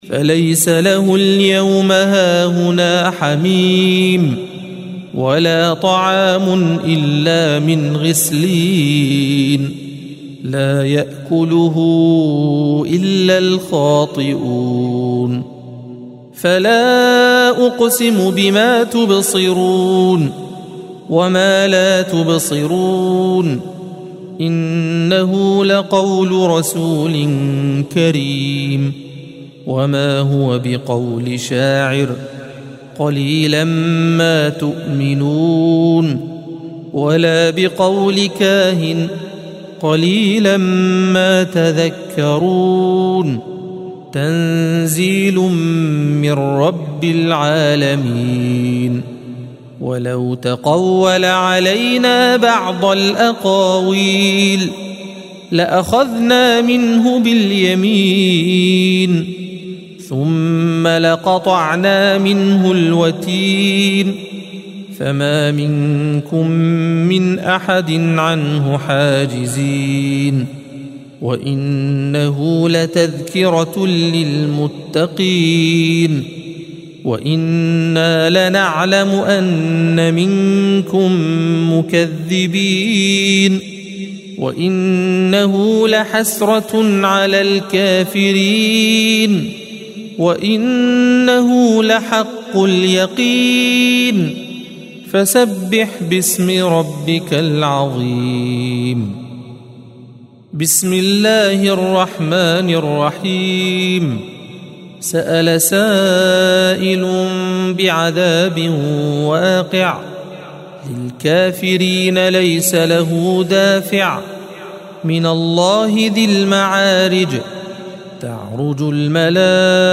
الصفحة 568 - القارئ